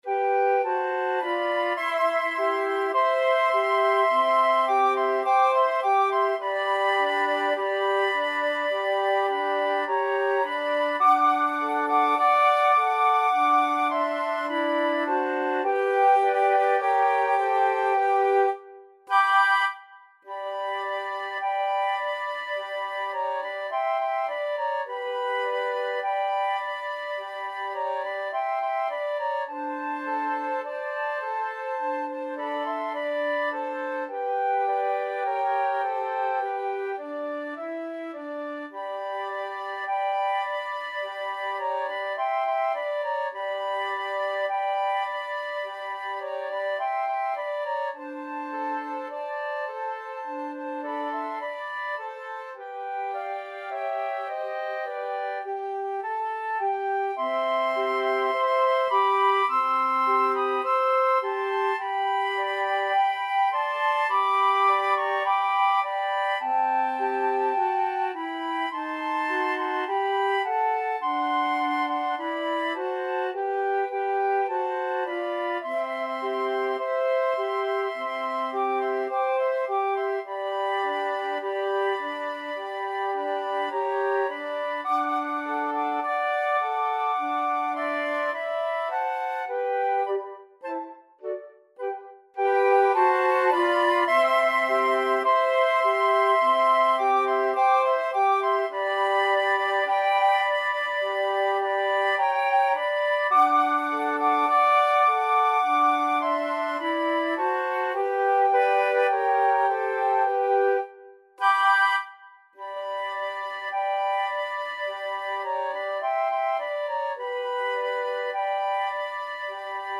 Flute 1Flute 2Flute 3Flute 4
Andantino =c.52 (View more music marked Andantino)
2/4 (View more 2/4 Music)
Flute Quartet  (View more Intermediate Flute Quartet Music)
Classical (View more Classical Flute Quartet Music)